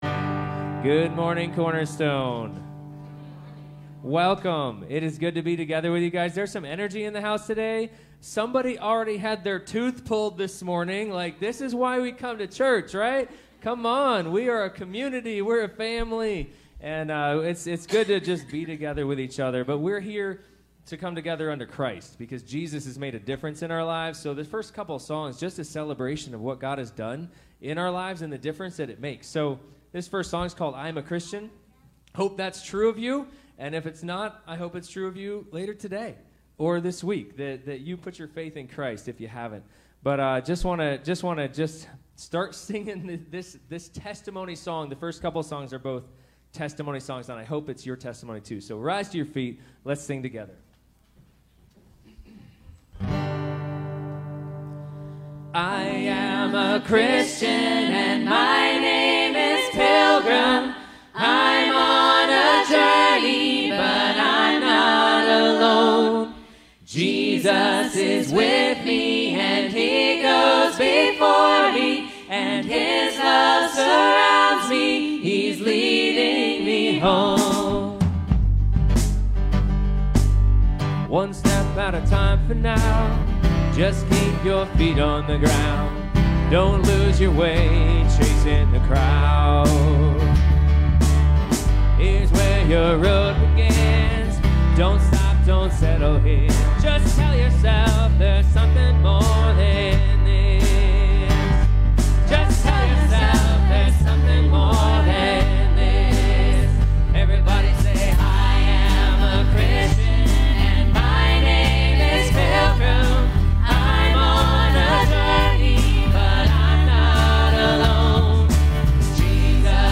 Passage: 2 Chronicles 34 Service Type: Sunday Morning Sermon